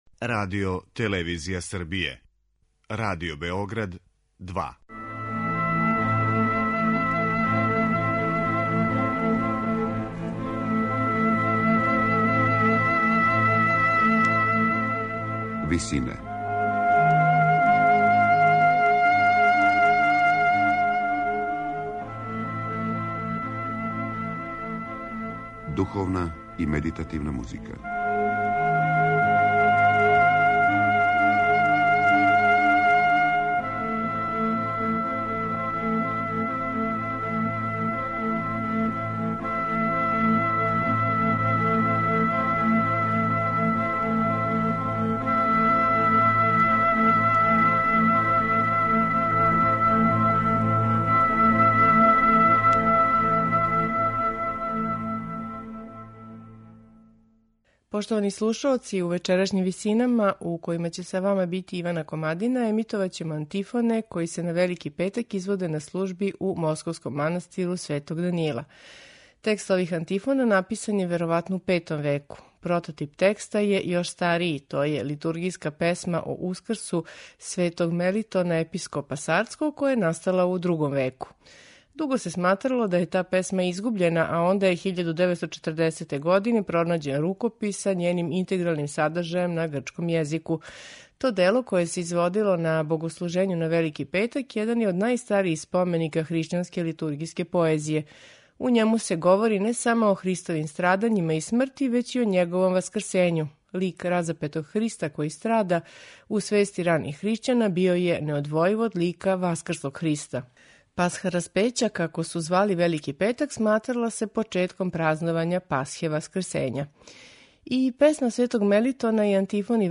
У вечерашњим Висинама емитоваћемо антифоне који се на Велики петак изводе на служби у московском манастиру Светог Данила.
Антифоне за Велики петак слушаћете у извођењу братства московског манастира Светог Данила.